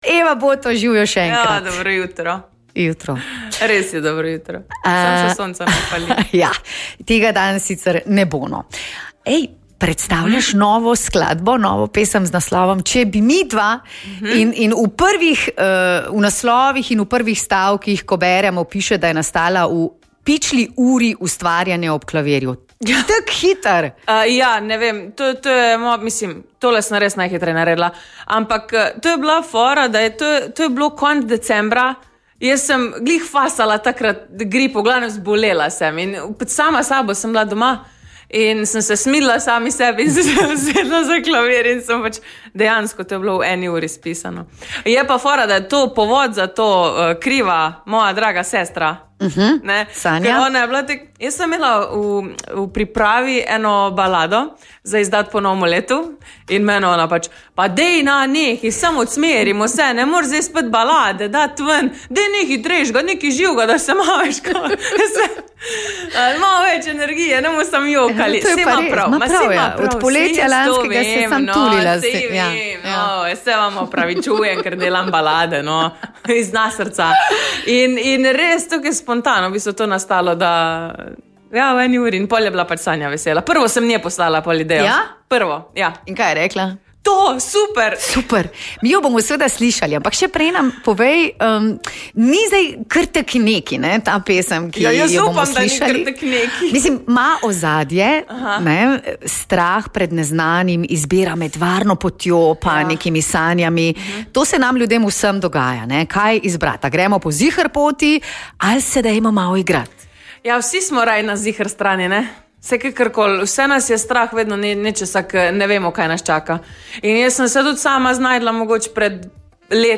Prihodnje leto bo slavila 15 let glasbene kariere, ko za svoje poslušalce pripravlja nov album in koncert v Cankarjevem domu. Več v pogovoru